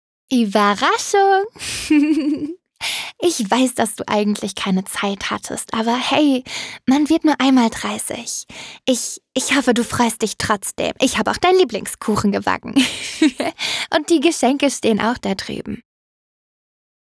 Freundin voller Vorfreude
Sie hat eine Überraschungsparty organisiert und ist glücklich ihr Meisterwerk zu präsentieren und damit eine Freude zu bereiten.